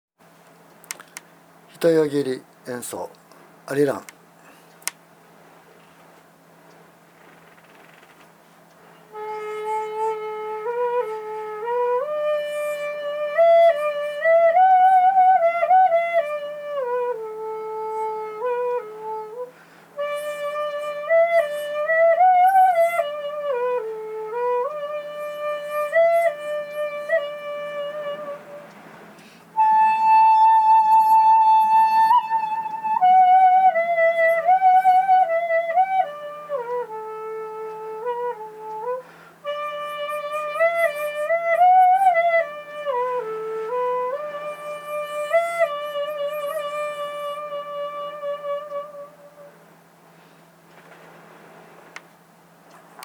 今度は一節切で同じ曲を吹きますが、同じ律音階ですので楽々と吹奏できます。
（一節切の「アリラン」音源）